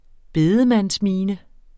Udtale [ ˈbeːðəmans- ]